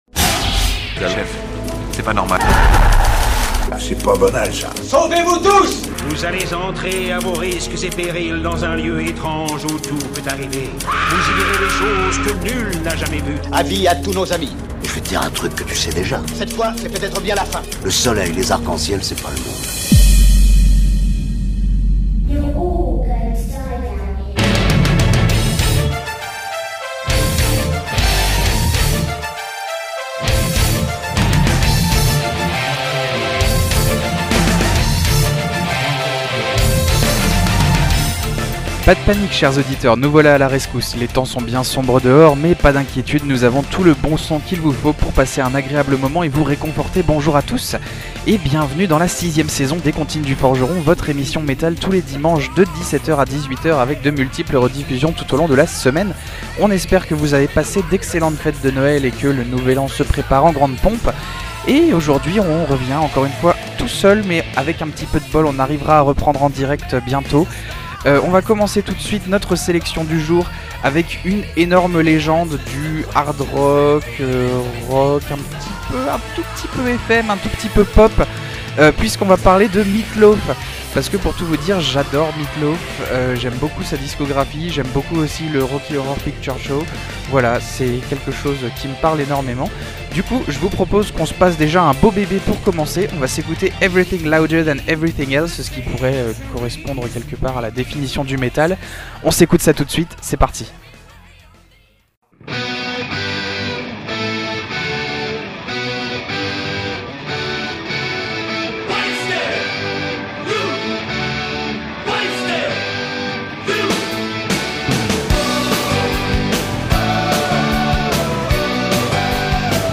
Les Comptines du Forgeron, Saison 6 épisode 7 – Radio PFM